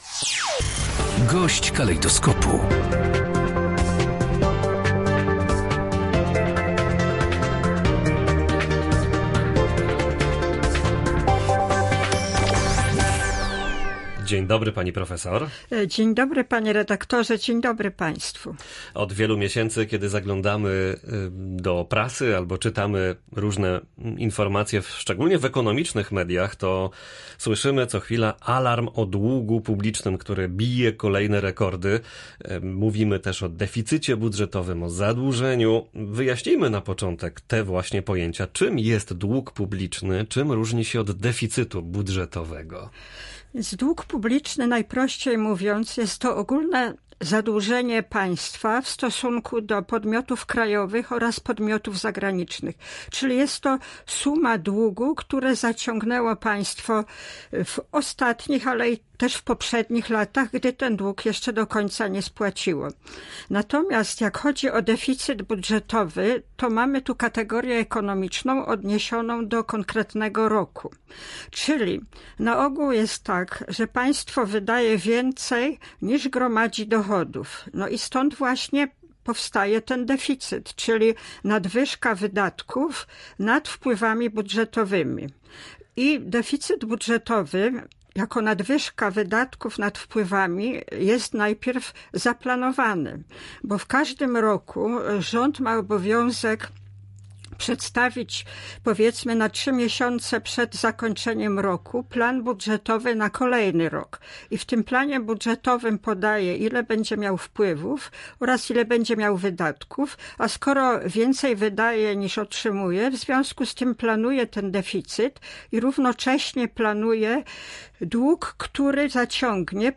Gość Polskiego Radia Rzeszów dodaje, że zadłużenie w ciągu ostatnich trzech miesięcy pokazuje zawrotne tempo wzrostu zadłużenia państwa. Na koniec marca dług publiczny wyniósł ponad 40 tys. zł na jednego mieszkańca Polski.